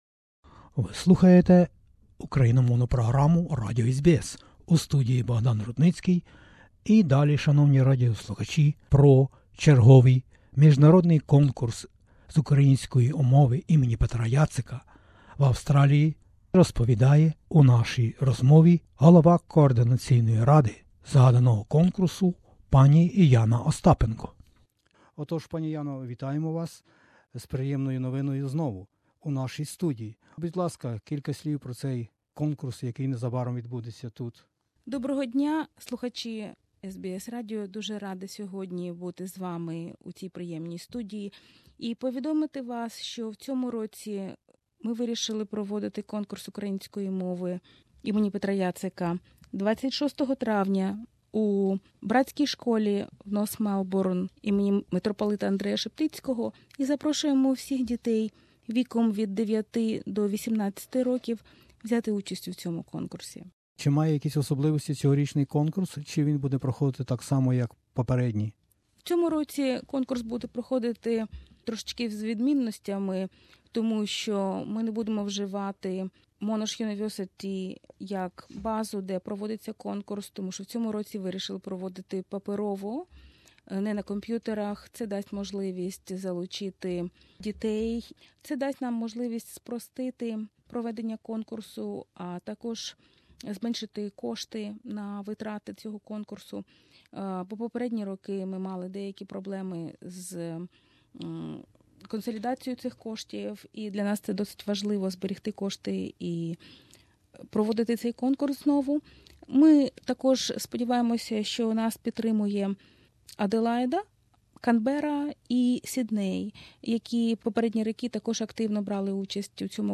SBS interview